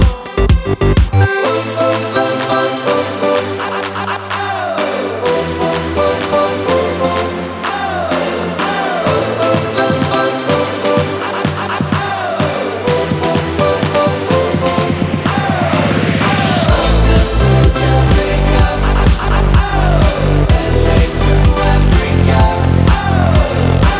For Solo Male Pop (2000s) 3:43 Buy £1.50